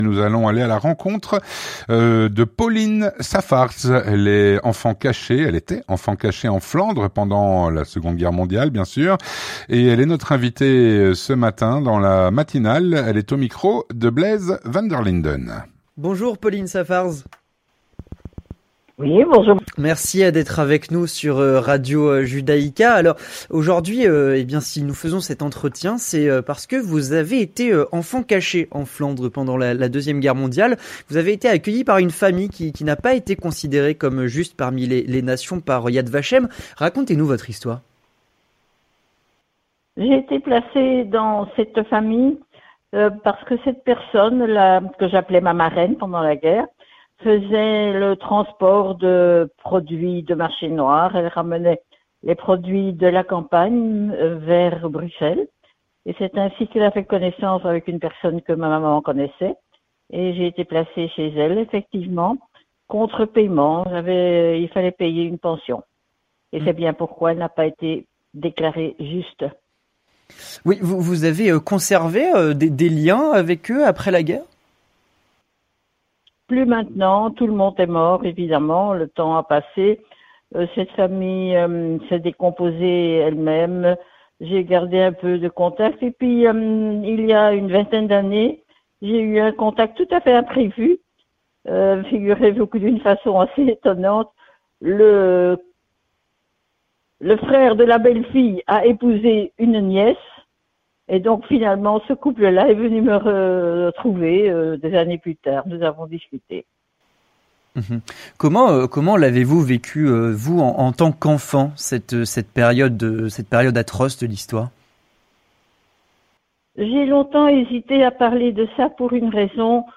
Elle répond aux questions